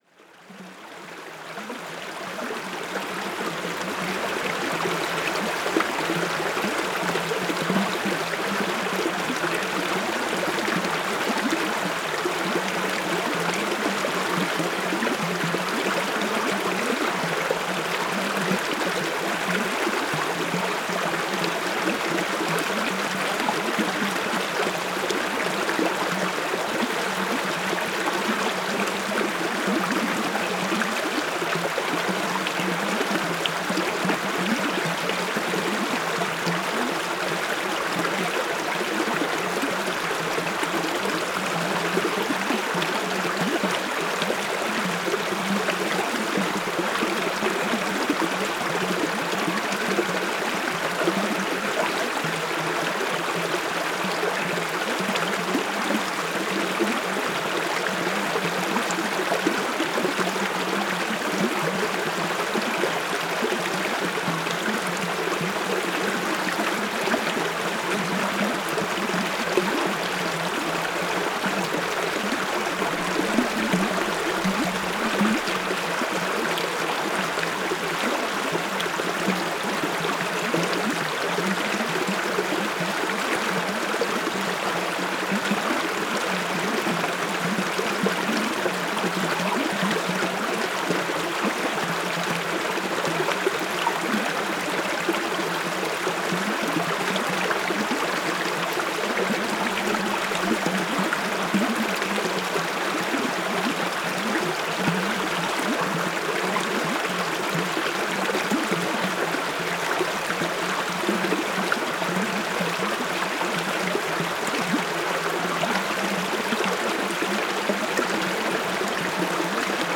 Paisagem sonora de escoamento de água junto à ponte na Rua Largo da Paciência em Zonho, Côta a 11 Março 2016.
NODAR.00517 – Côta: Escoamento de água junto à ponte na Rua Largo da Paciência em Zonho